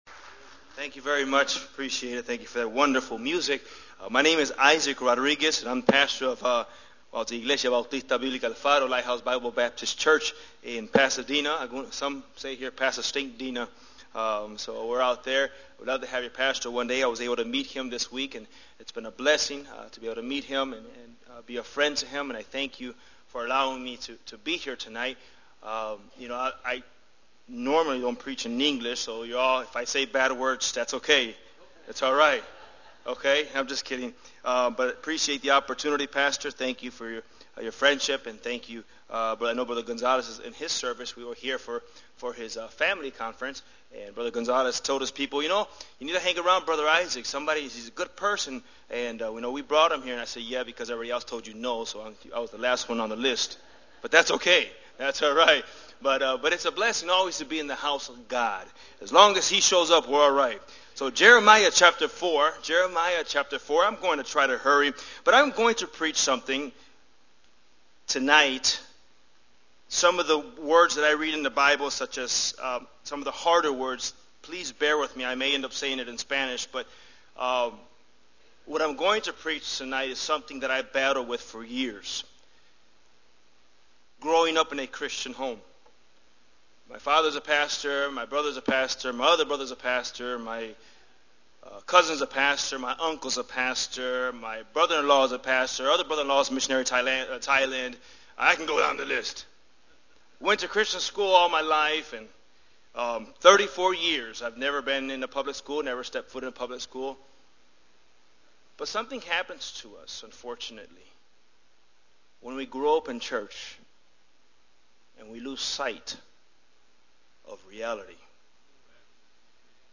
Sunday Evening
Sermons